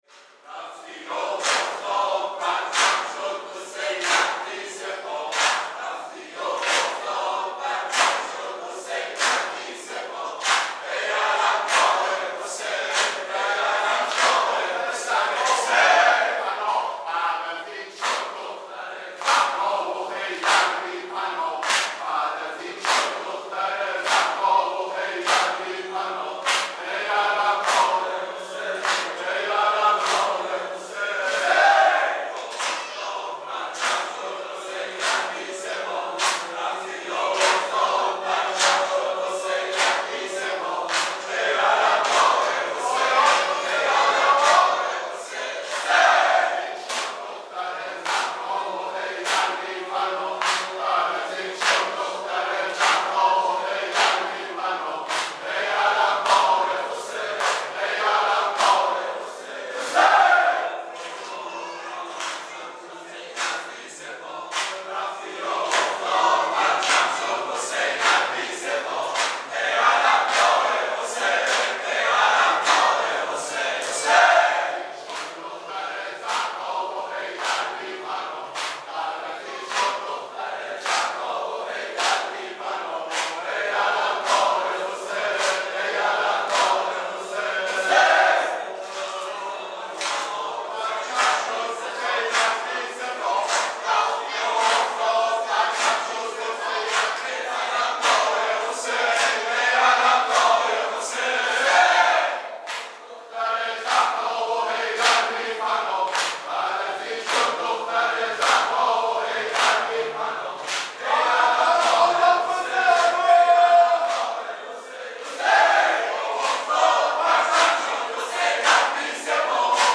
0 0 دودمه | رفتی و افتاد پرچم شد حسینت بی سپاه
محرم ۱۴۴۴ | شب نهم